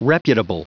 Prononciation du mot reputable en anglais (fichier audio)
Prononciation du mot : reputable